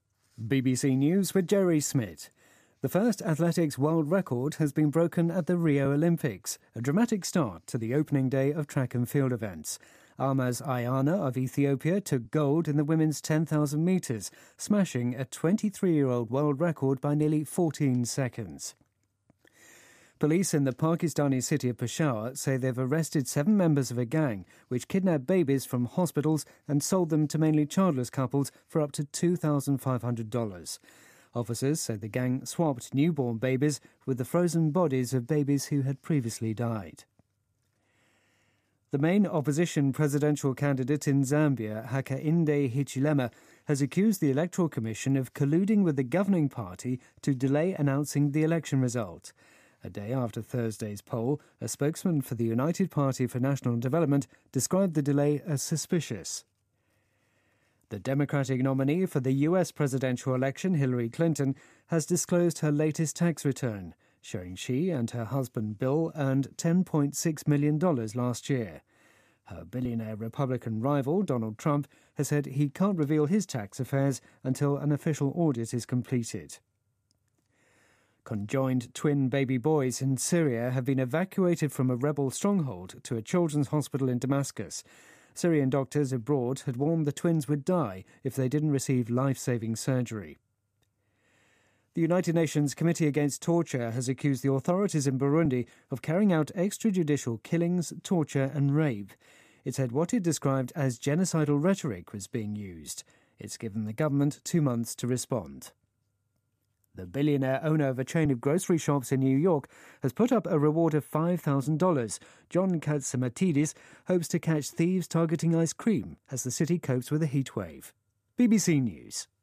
BBC news,希拉里公开“报税单”倒逼特朗普